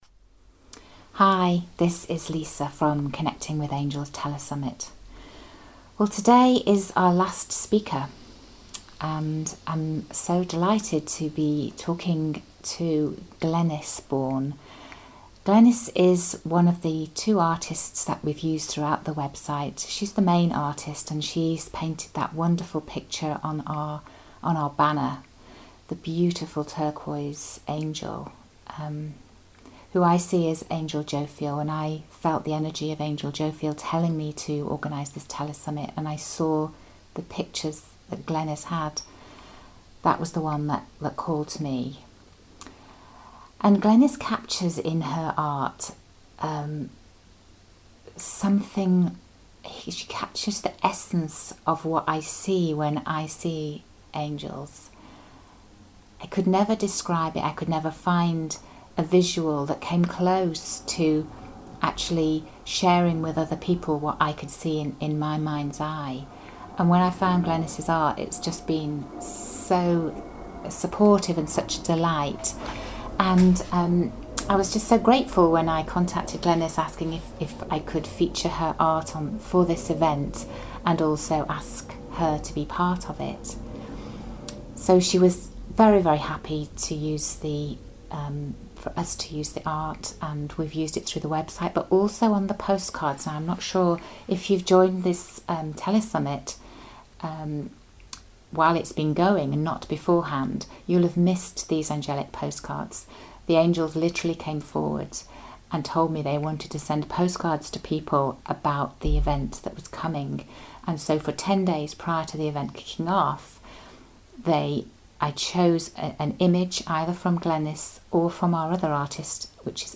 Recorded at the recent Angel Telesummit.